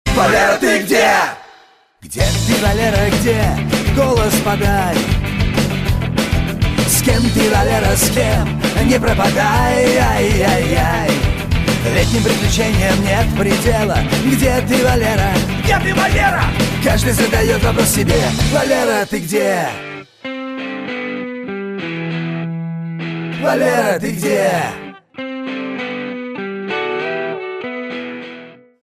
• Качество: 128, Stereo
мужской вокал
громкие
веселые
русский рок